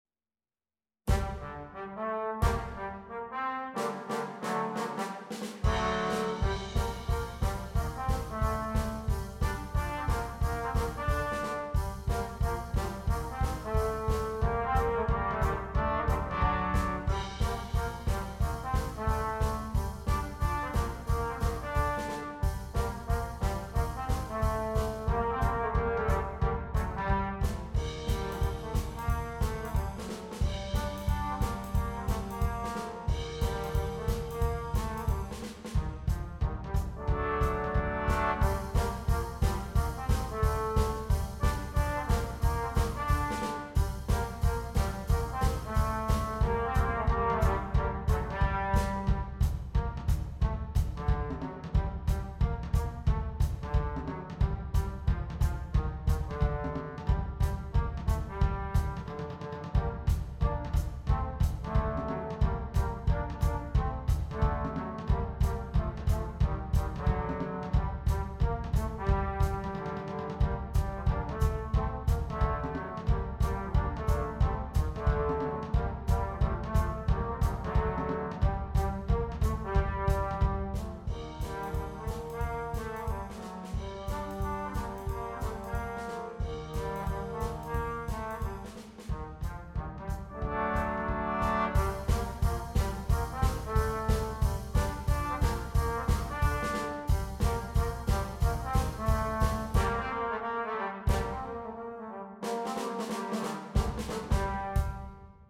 jump swing